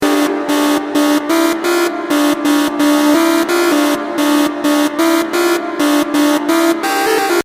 dutch-house_14264.mp3